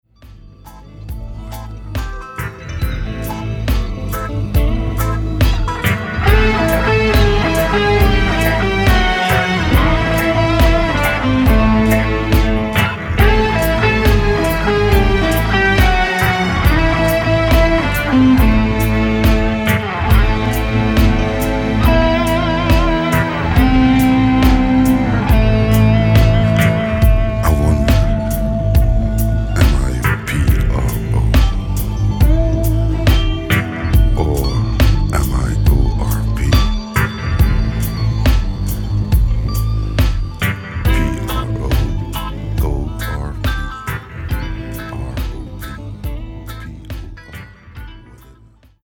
sounds like a super dubed out french reggae version